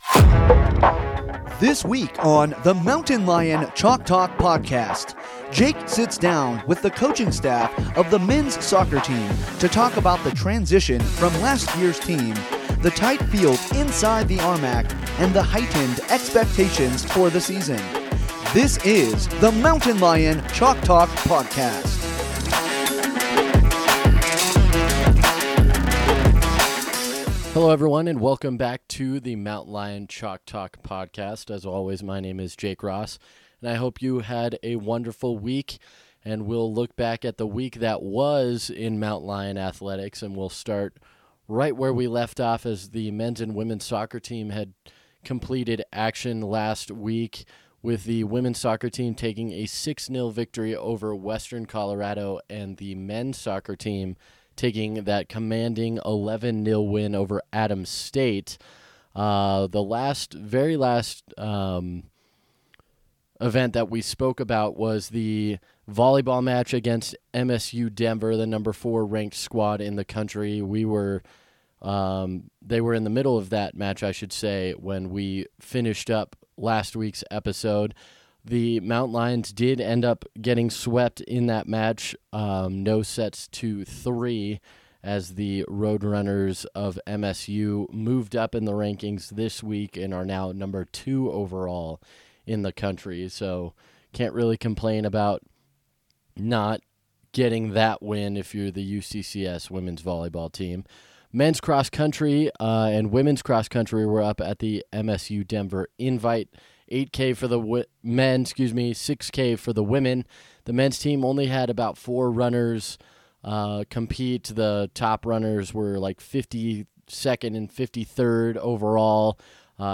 sits down with the coaching staff of the men's soccer team to talk about the transition from last year's team, the tight field inside the RMAC, and the heightened expectations for this season.